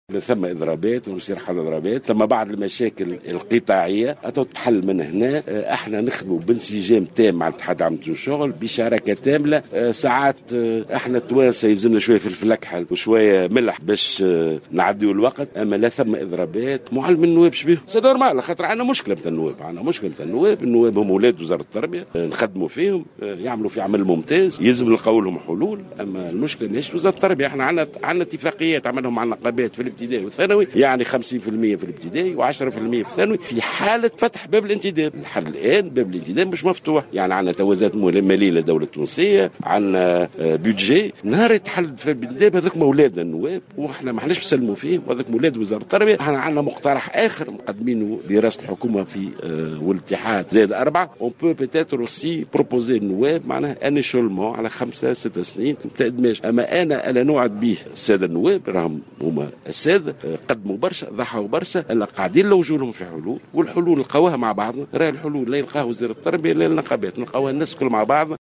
تعهد وزير التربية ناجي جلول، في تصريح للجوهرة أف أم، اليوم السبت، على هامش اعطائه اشارة انطلاق بناء الحي السكني التربوي بالمنيهلة، بإيجاد حلول لفائدة المعلمين والأساتذة النواب بالتعاون بين مختلف الأطراف المعنية من سلطة إشراف ونقابة وأساتذة.